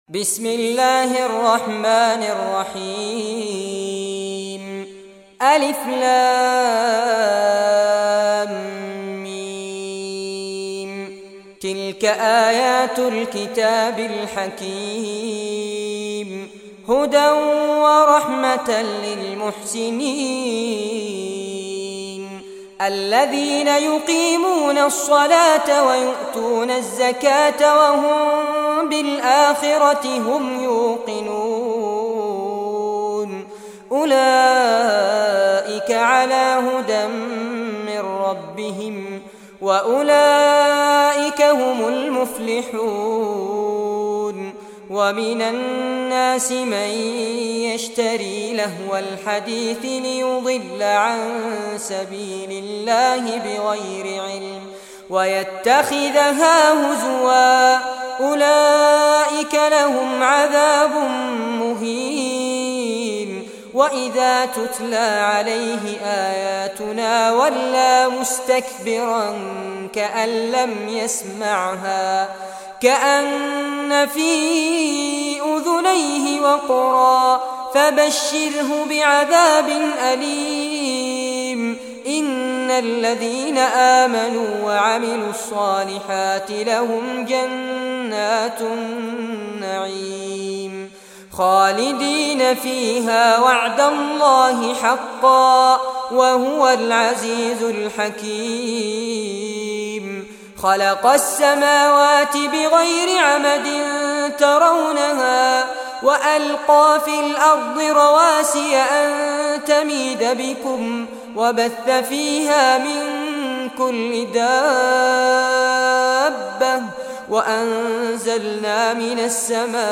Surah Luqman Recitation by Fares Abbad
Surah Luqman, listen or play online mp3 tilawat / recitation in Arabic in the beautiful voice of Sheikh Fares Abbad.